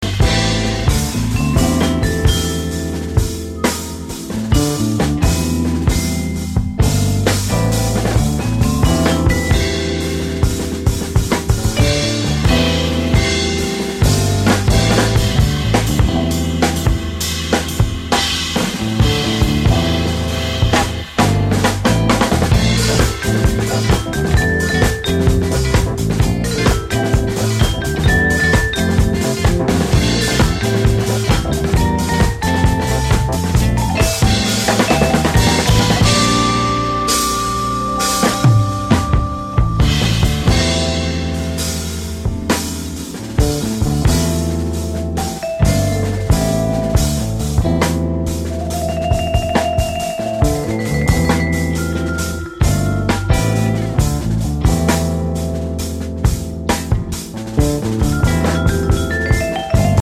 Real jazz-funk with an hip hop attitude.